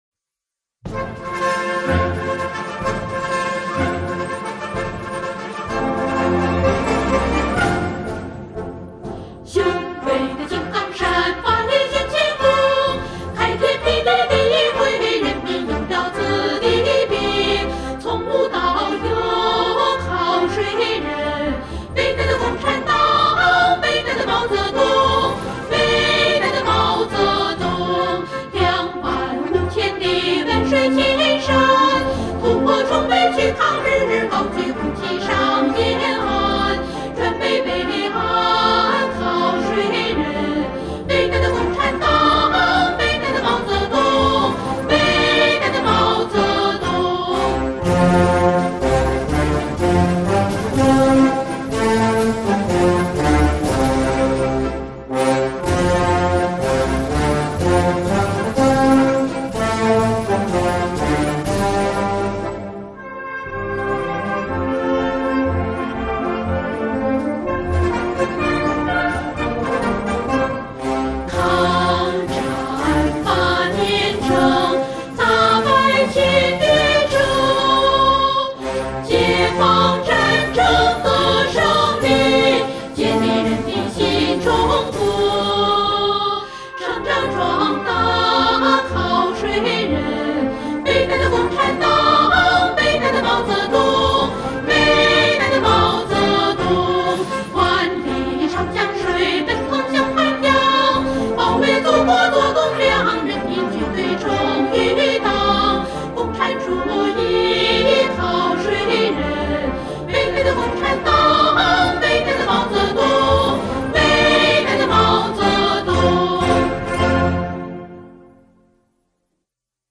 女声：